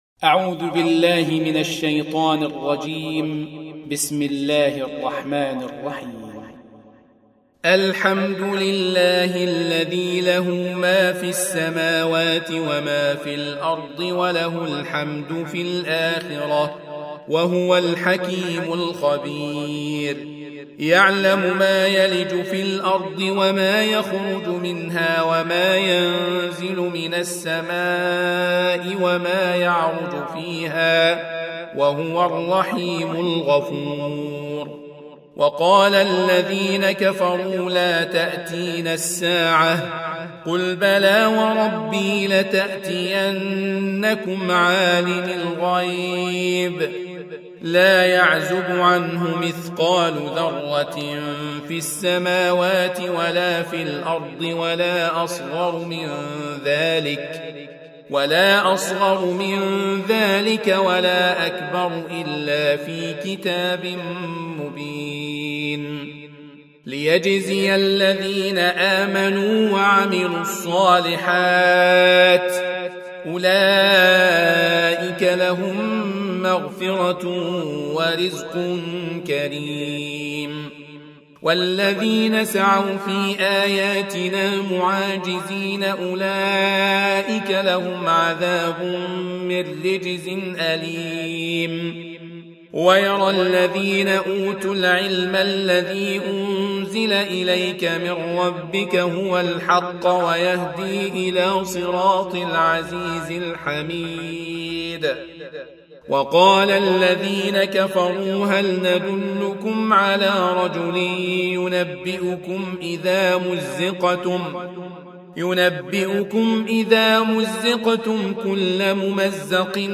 Surah Sequence تتابع السورة Download Surah حمّل السورة Reciting Murattalah Audio for 34. Surah Saba' سورة سبأ N.B *Surah Includes Al-Basmalah Reciters Sequents تتابع التلاوات Reciters Repeats تكرار التلاوات